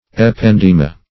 ependyma - definition of ependyma - synonyms, pronunciation, spelling from Free Dictionary
Ependyma \Ep*en"dy*ma\, n. [NL., fr. Gr. ? an upper garment;